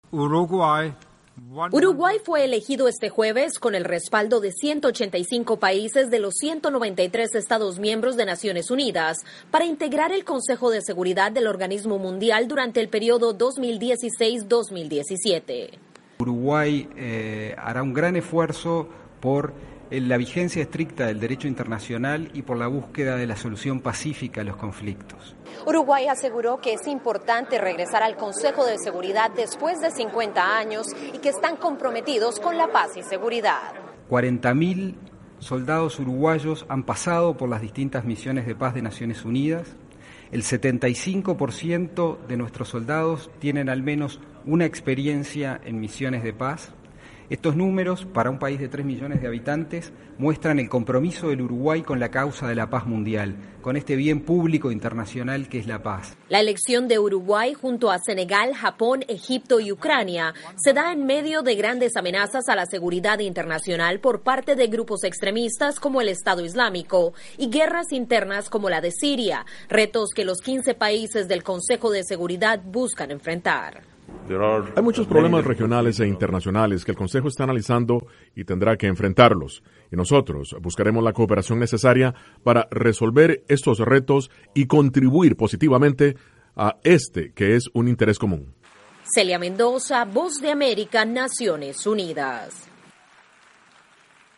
Después de 50 años, Uruguay vuelve a ser parte del Consejo de Seguridad de las Naciones Unidas. Informa desde Nueva York